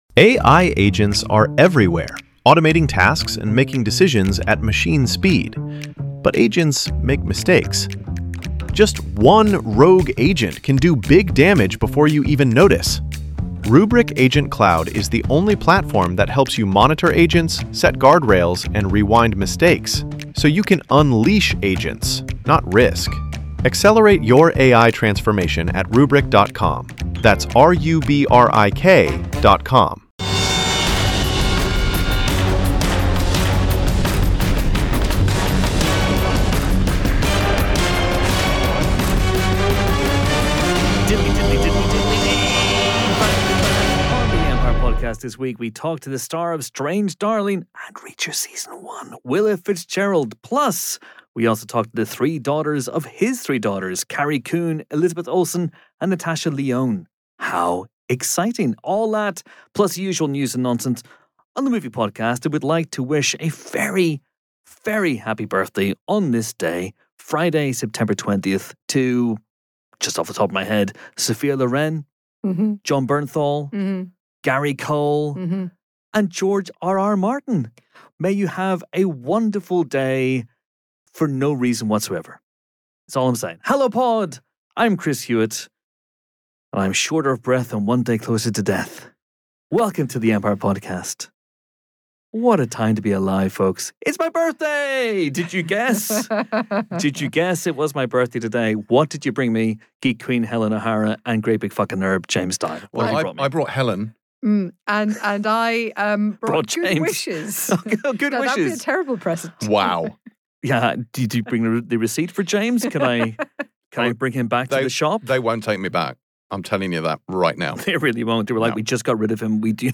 [23:05 - 40:27 approx] and c) interviewing the powerhouse trio who star in new movie His Three Daughters, Carrie Coon, Elizabeth Olsen and Natasha Lyonne.